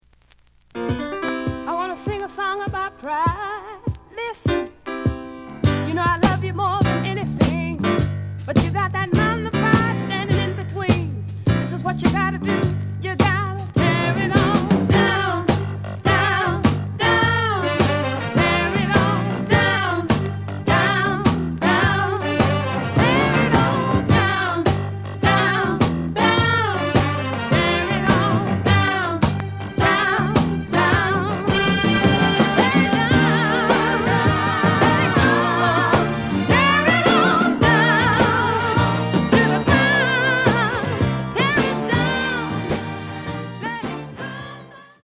The listen sample is recorded from the actual item.
●Genre: Soul, 70's Soul